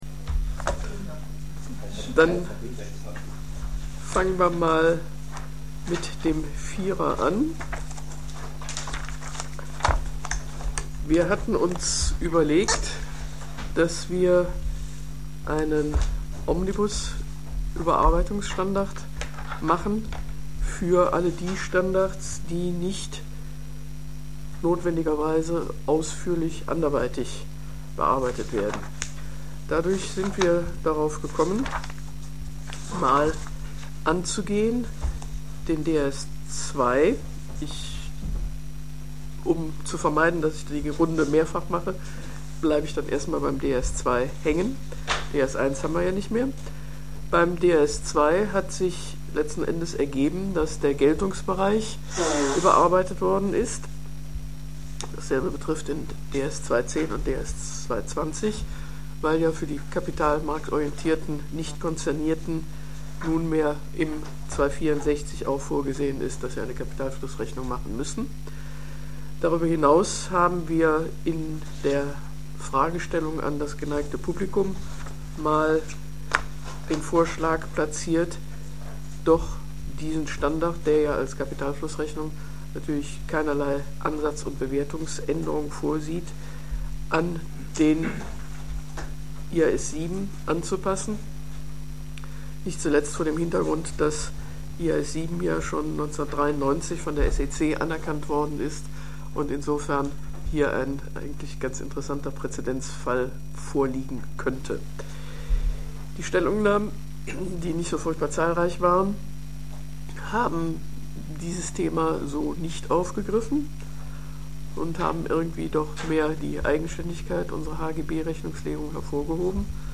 139. DSR-Sitzung und 15. Öffentliche Sitzung des DSR • DRSC Website